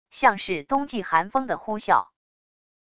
像是冬季寒风的呼啸wav音频